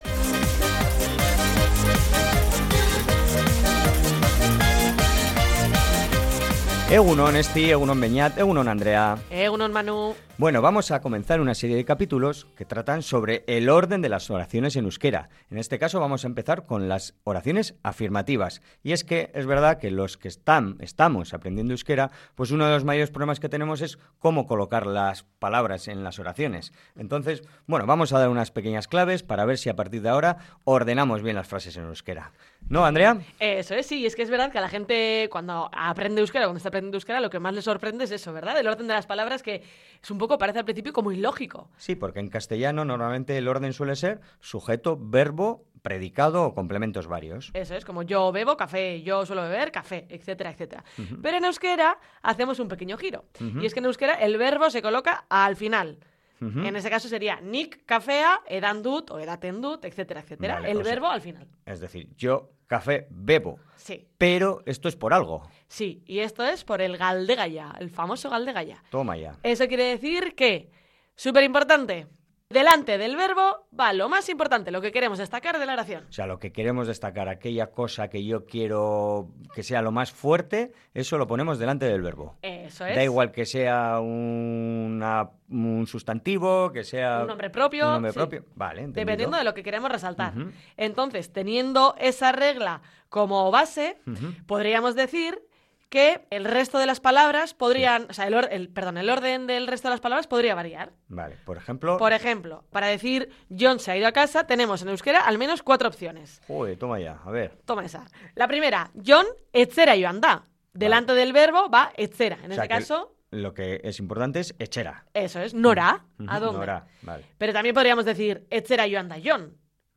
Para ilustrarlo, en la entrevista se ha utilizado el ejemplo de «Jon se ha ido a casa» (Jon etxera joan da).